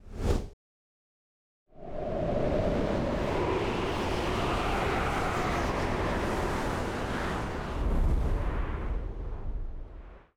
Babushka / audio / sfx / Battle / Vesna / SFX_Schlappentornado_05.wav
SFX_Schlappentornado_05.wav